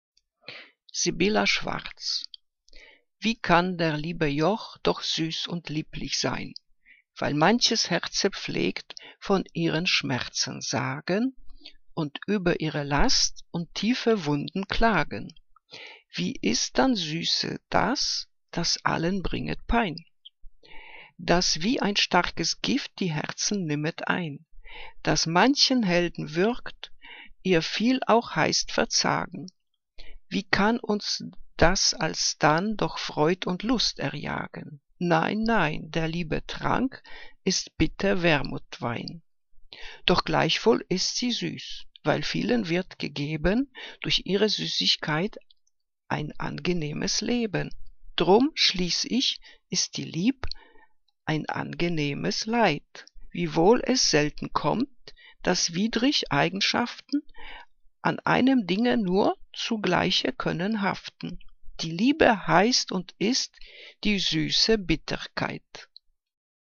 Liebeslyrik deutscher Dichter und Dichterinnen - gesprochen (Sibylla Schwarz)